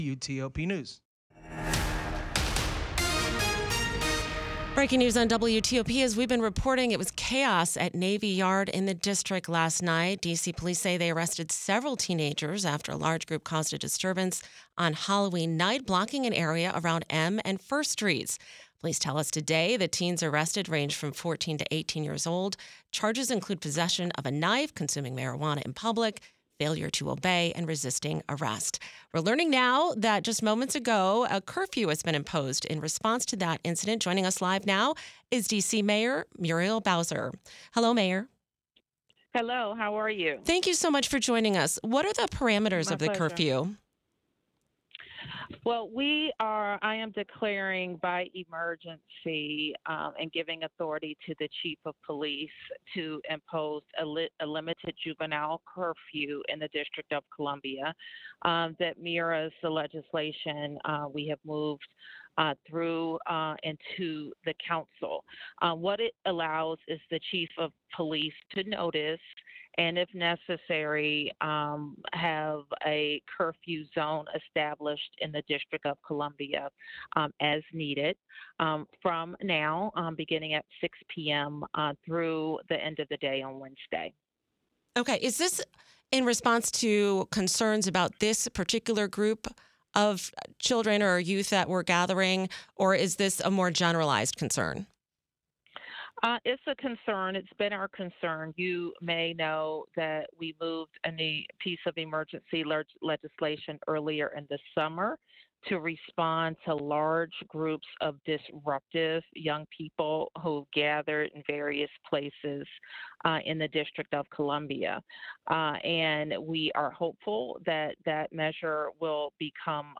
D.C. Mayor Muriel Bowser joins WTOP to discuss the youth curfew in effect through Wednesday, Nov. 5.
“I find it necessary to ensure that the police can disperse large groups of teens,” Bowser told WTOP in an exclusive interview after her announcement.